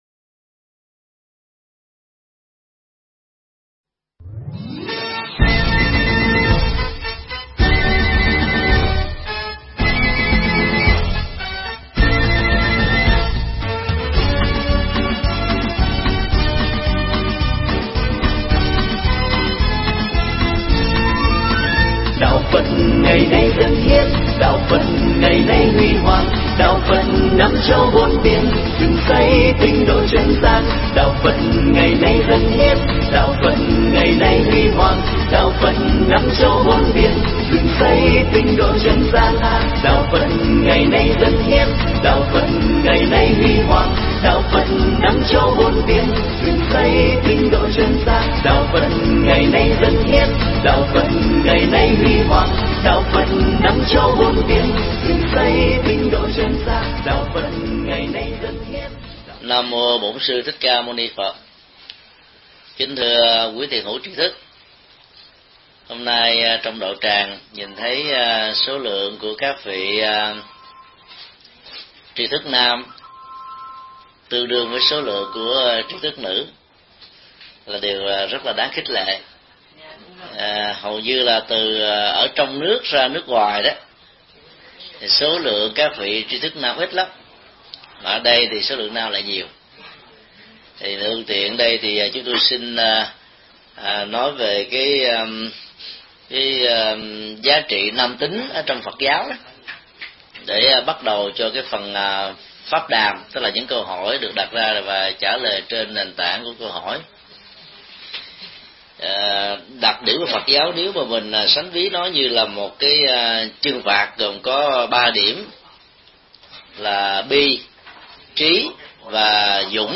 Mp3 Thuyết Giảng Tuổi trẻ và pháp môn – Thượng Tọa Thích Nhật Từ Giảng tại Đạo tràng Tỉnh Giác, ngày 20 tháng 8 năm 2008